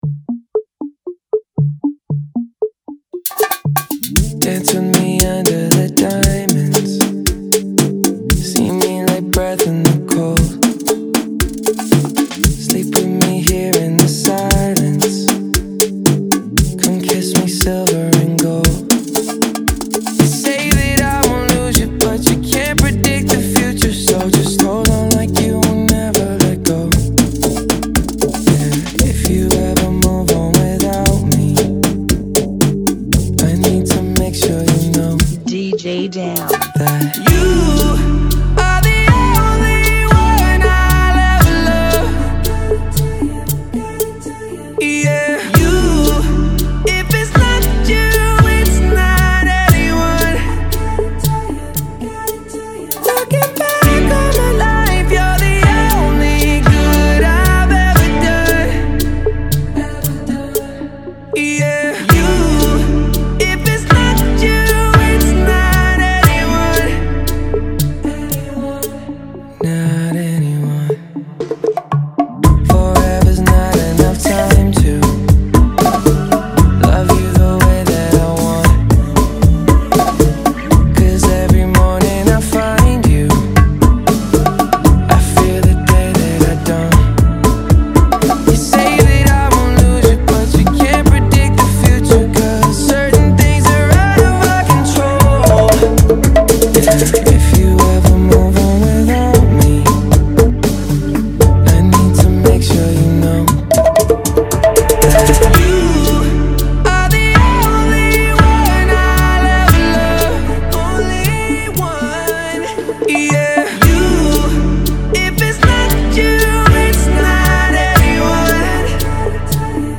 116 BPM
Genre: Bachata Remix